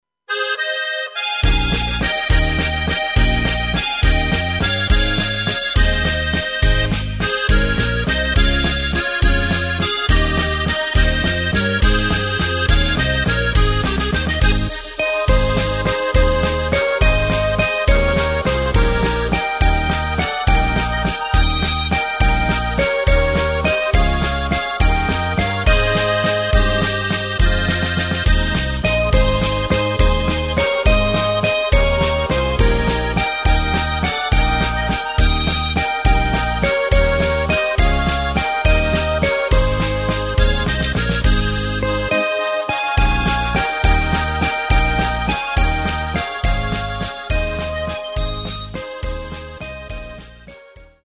Takt:          3/4
Tempo:         208.00
Tonart:            Ab
Walzer Tradit.